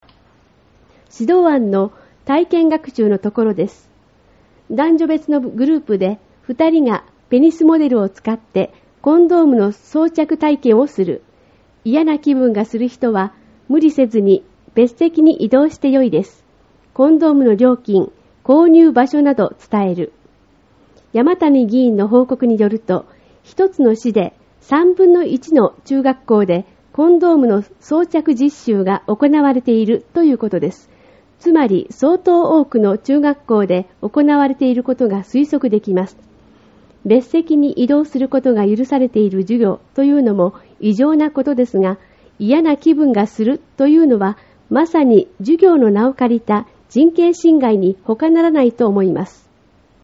音声による説明 　指導案の体験学習のところです。